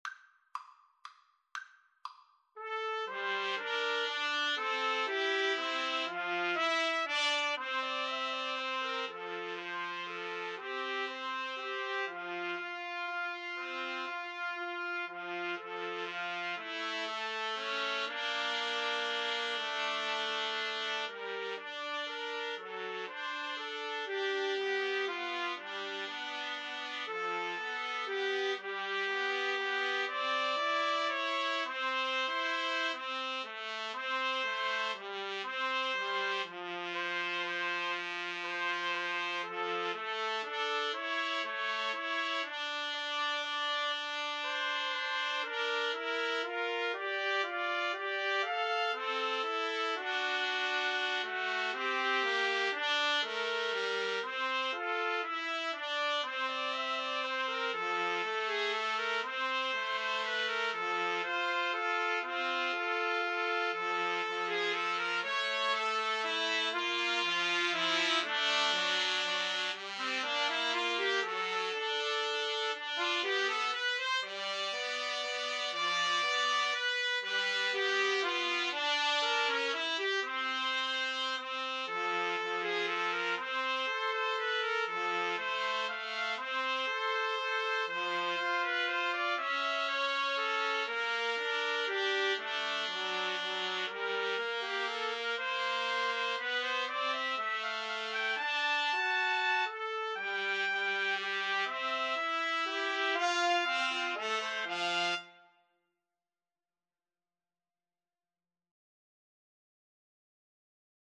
Trumpet 1Trumpet 2Trumpet 3
3/4 (View more 3/4 Music)
= 120 Tempo di Valse = c. 120